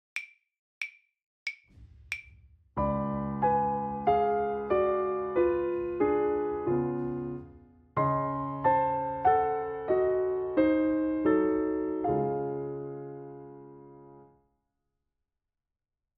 • Déchiffrage avec piano (Texte, Rythme et Intervalle)
91 - Page 42 - déchiffrage 1 - piano seul